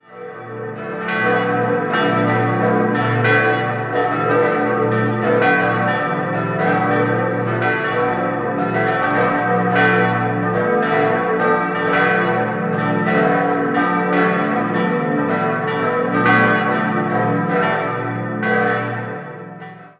Dabei erhielt St. Peter ein neugotisches Gewand. 5-stimmiges Geläut: h°-d'-e'-fis'-a' Die vier kleineren Glocken wurden 1949, die große 1954 von der Gießerei Otto in Bremen-Hemelingen gegossen.